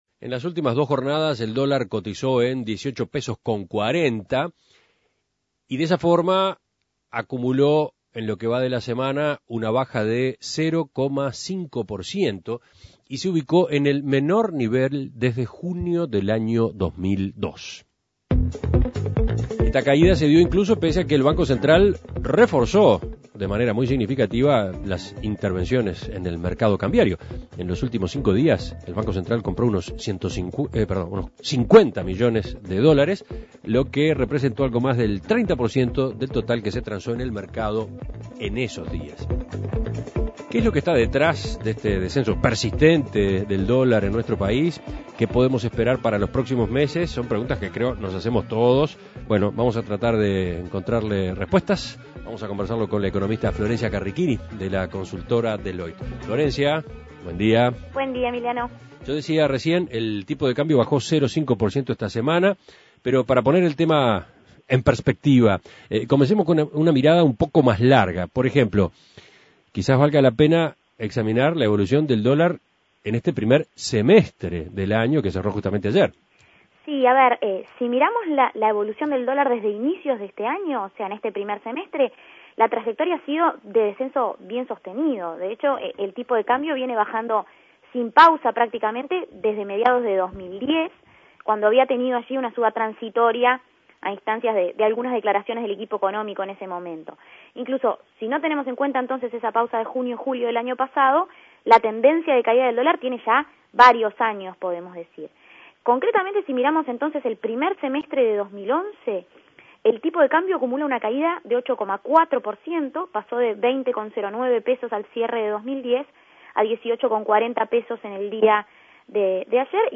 Análisis Económico El tipo de cambio cayó por debajo de los 18,50 pesos en las últimas jornadas.